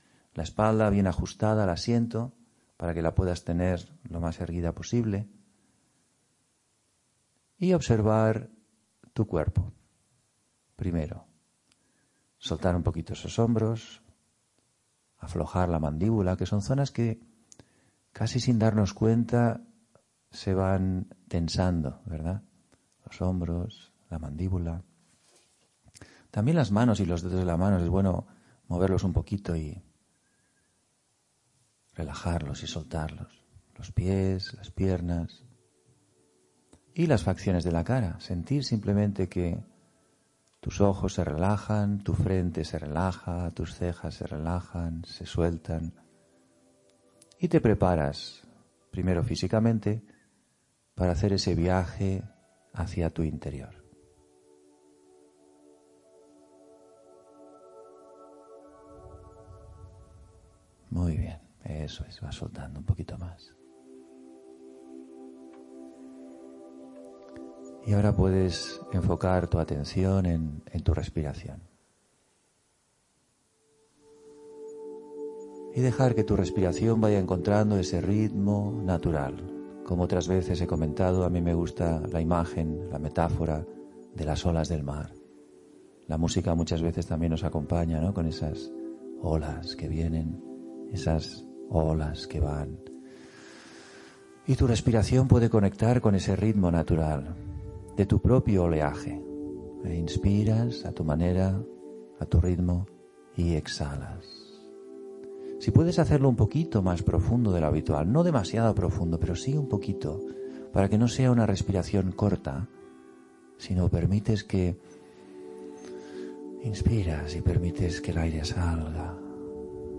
meditacion_confia_en_la_vida.mp3